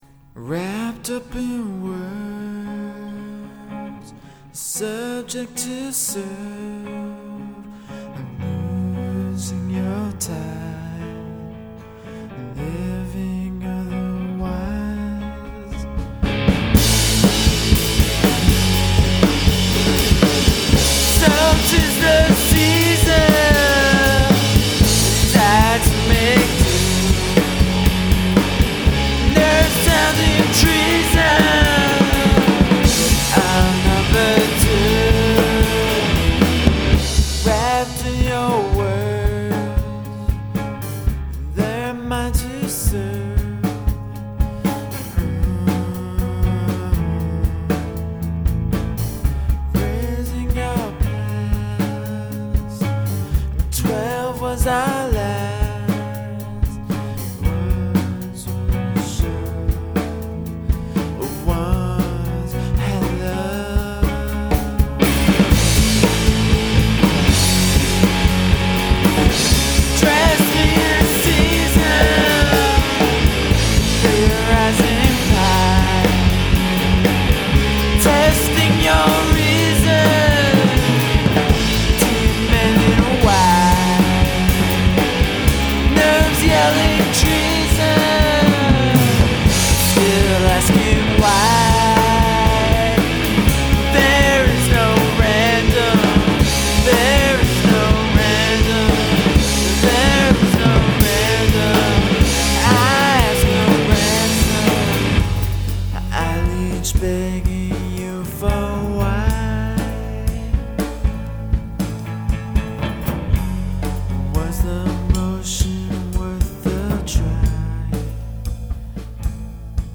drums. Recorded using Fostex E-8 1/4" 8-track recorder.